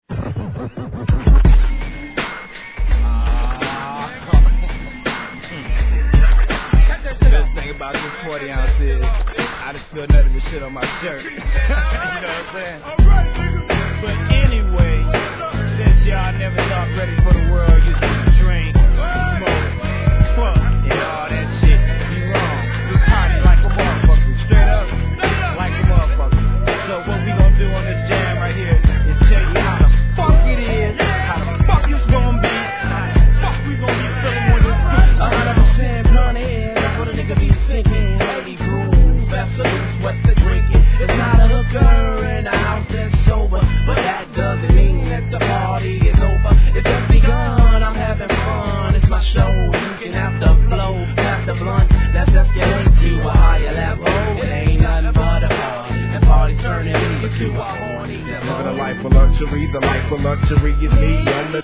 1. G-RAP/WEST COAST/SOUTH
1996年のミディアム好FUNK!